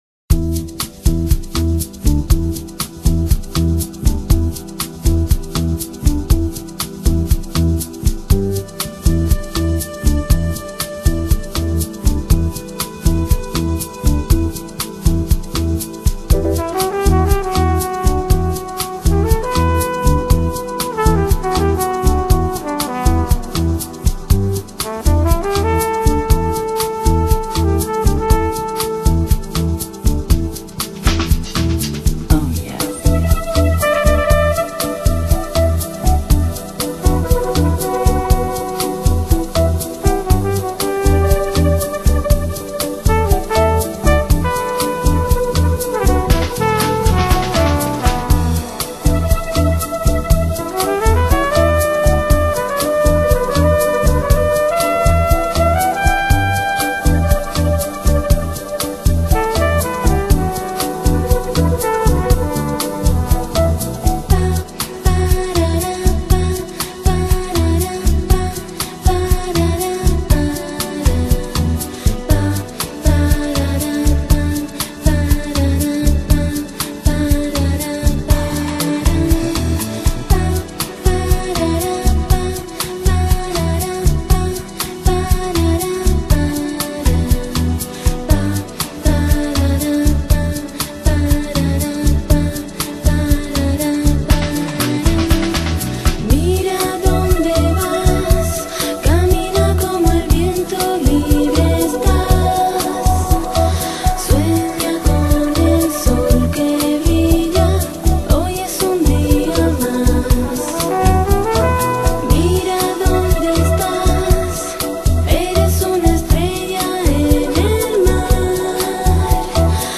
Music Genre: Chillout - Lounge - Downtempo